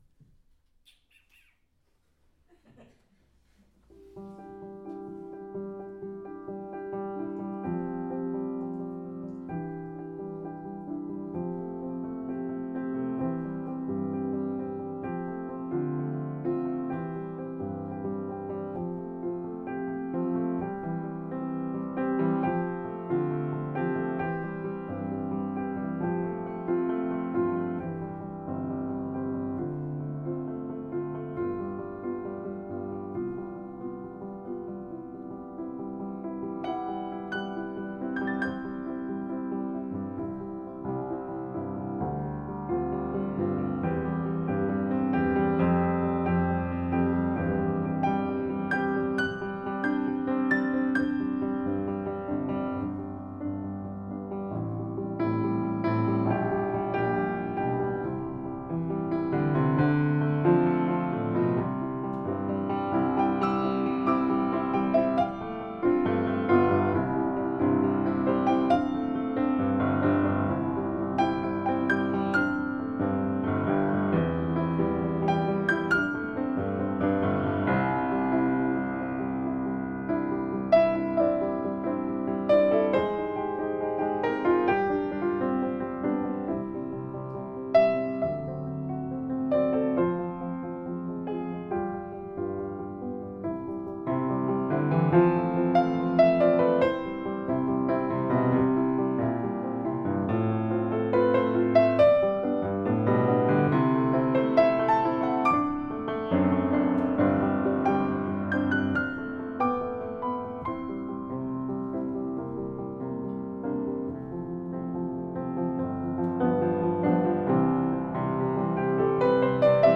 Une vieille connaissance refait surface (enregistrement public)
calme do majeur intimiste tranquille
une vieille connaissance.mp3 Suite des improvisations enregistrées en public lors de mon concert du 24 février 2011 au Salon Adjuvance, sur piano Bechstein. Pour la petite histoire, ce thème m'a été proposé par un spectateur qui avait retrouvé dans le public une personne qu'il n'avait pas rencontrée depuis 5-6 ans (à moins que ce ne soit le contraire, mais peu importe !)